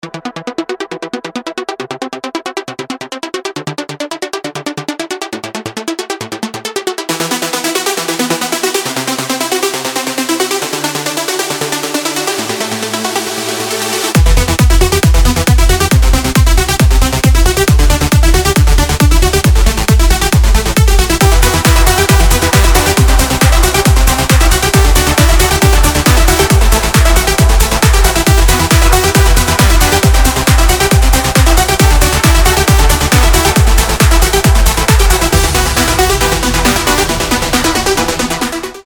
Клубные
Классический Транс